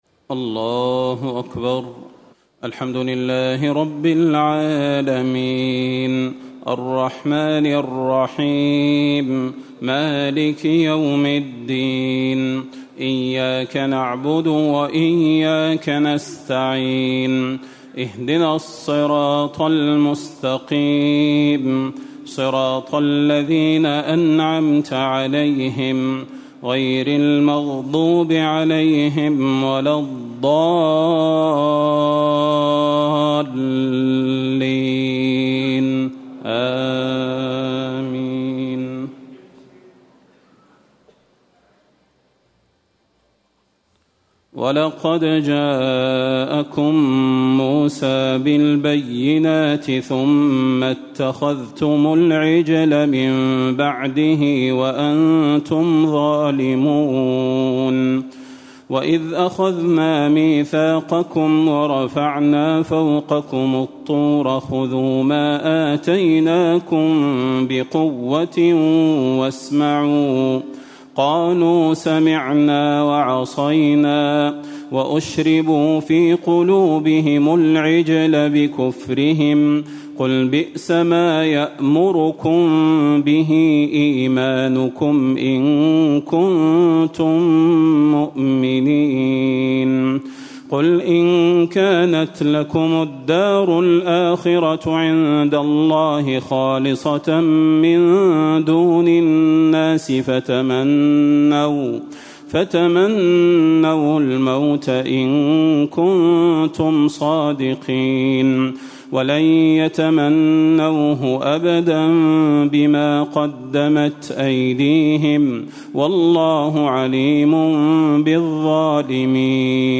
تهجد ليلة 21 رمضان 1437هـ من سورة البقرة (92-141) Tahajjud 21 st night Ramadan 1437H from Surah Al-Baqara > تراويح الحرم النبوي عام 1437 🕌 > التراويح - تلاوات الحرمين